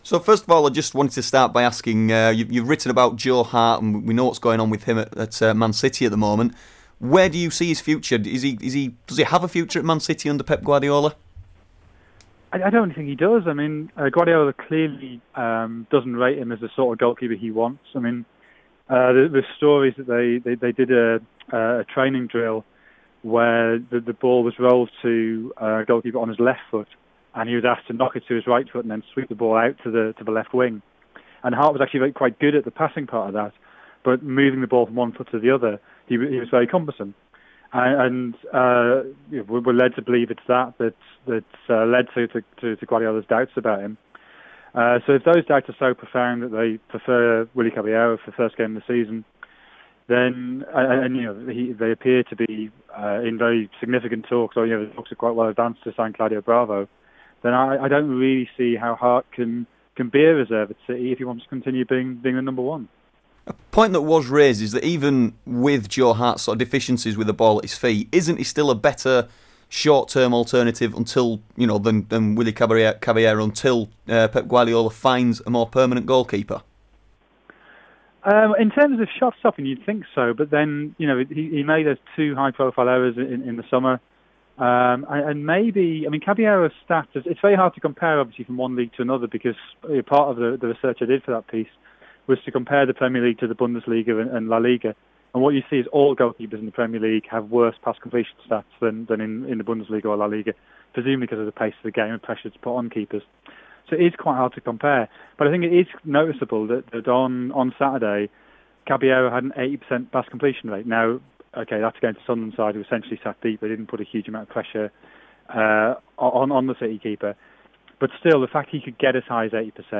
Football writer Jonathan Wilson speaking